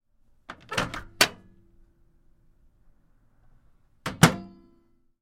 微波炉开闭；1
描述：微波炉门打开和关闭。
Tag: 厨房 打开 关闭 关闭 SLAM 塑料 微波 烹饪 按钮